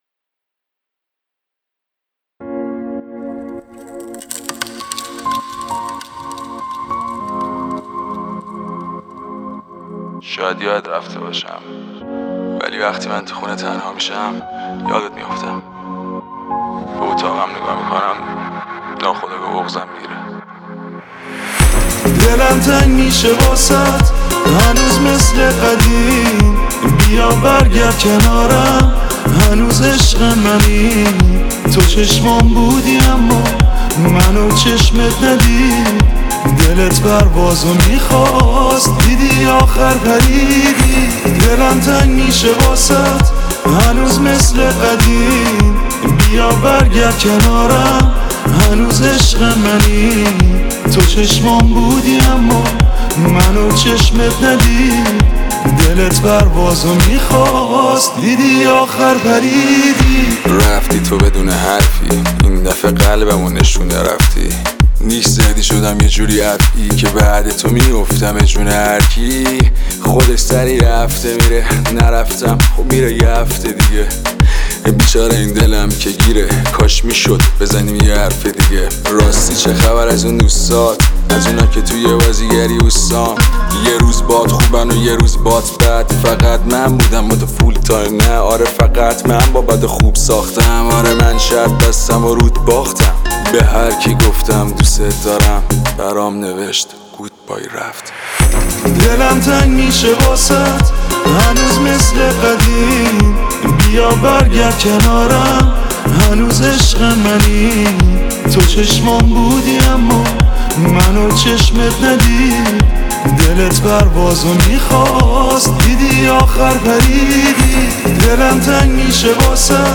رپ جدید